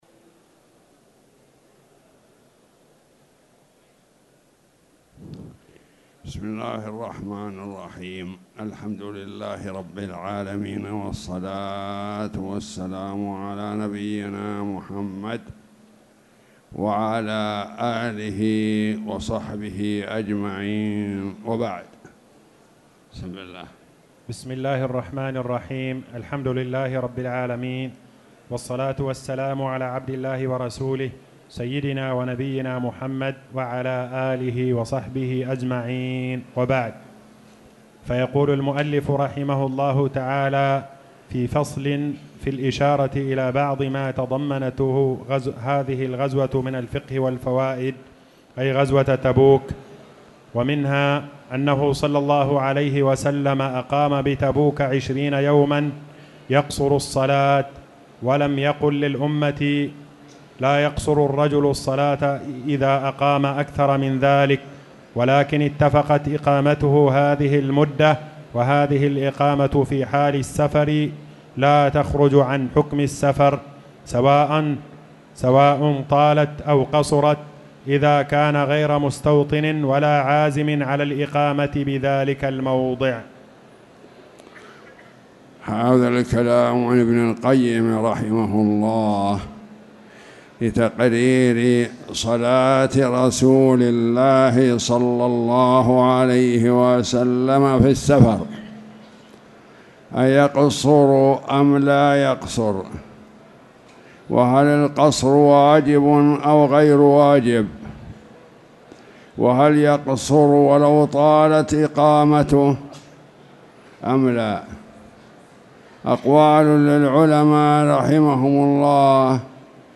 تاريخ النشر ١٠ ربيع الأول ١٤٣٨ هـ المكان: المسجد الحرام الشيخ